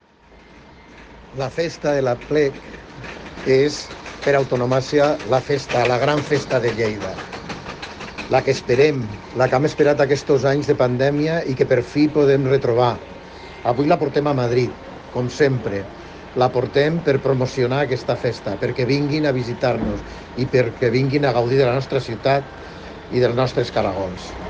Tall de veu del tinent d'alcalde Paco Cerdà sobre la presentació del menú de la Cuina del Caragol a Madrid, amb la presència de Turisme de Lleida